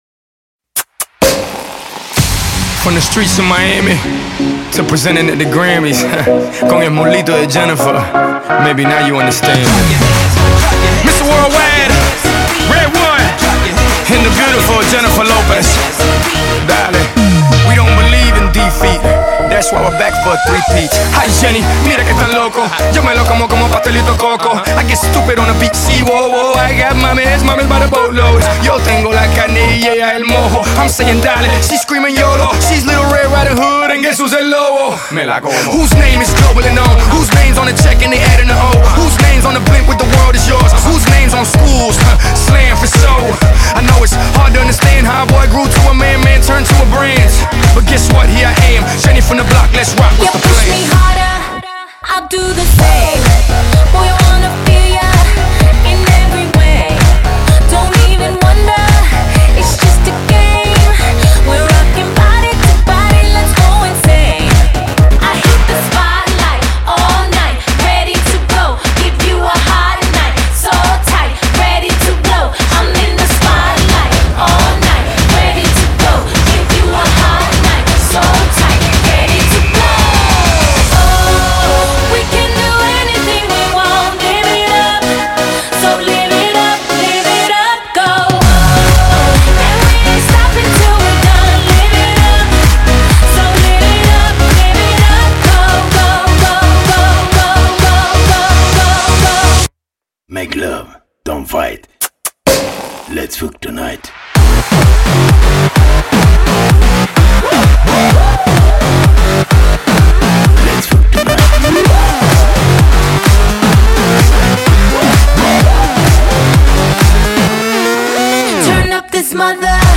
ژانر: هیپ هاپ - پاپ رپ- پاپ